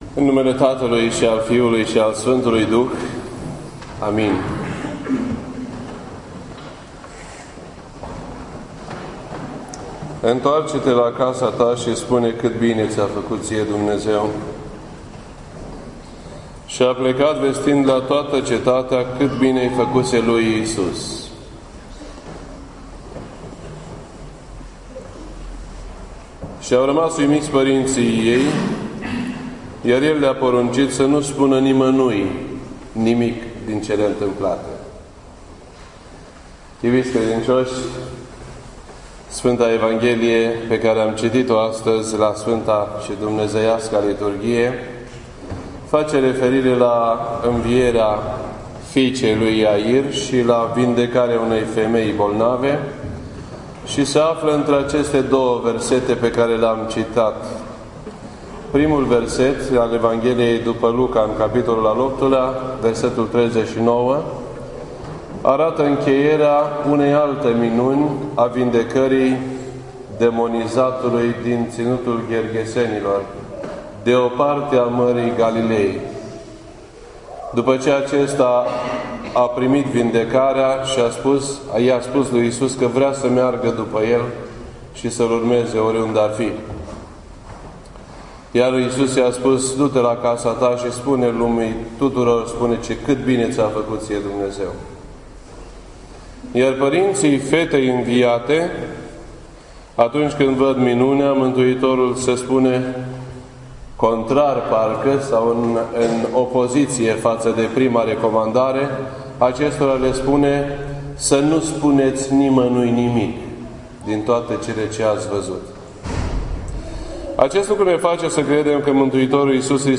This entry was posted on Sunday, November 9th, 2014 at 12:22 PM and is filed under Predici ortodoxe in format audio.